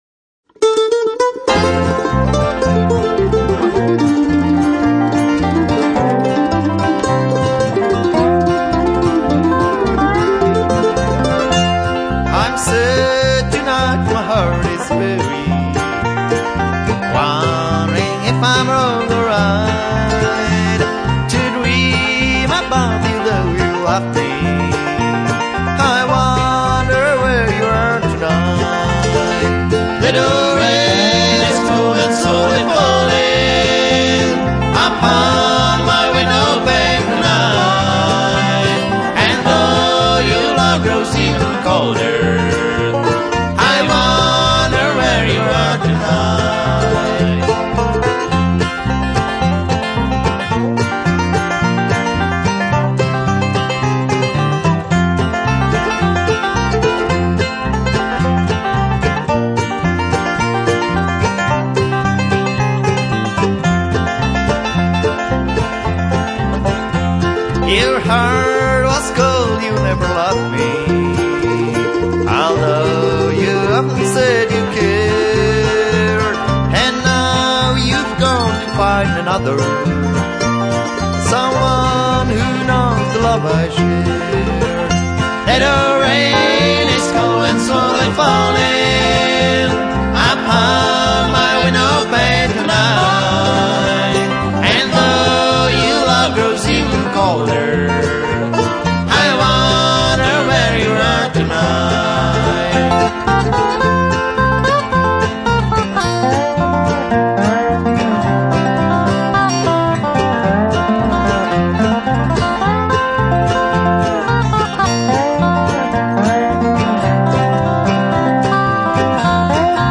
guitar, vocal
banjo, vocal
dobro, vocal